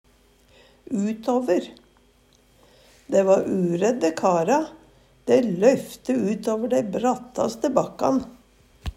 utåver - Numedalsmål (en-US)